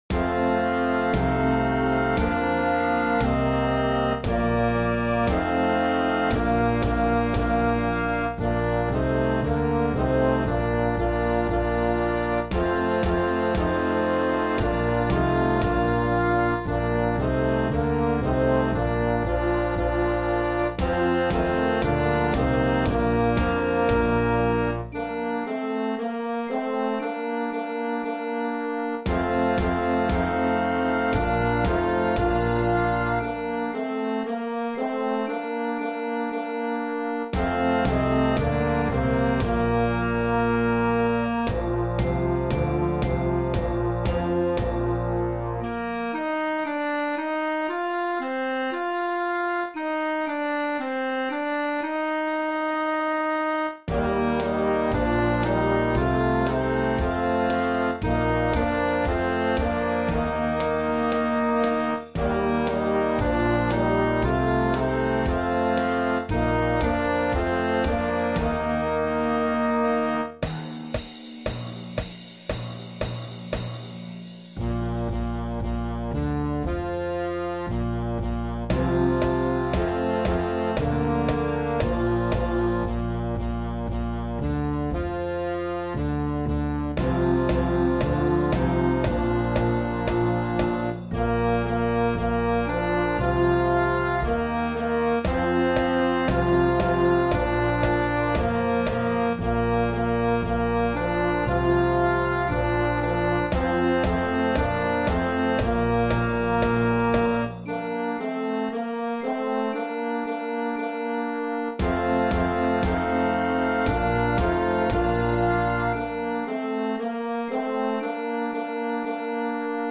Full Band Charts, one part per instrument.
Computer MP3 file
A medley of songs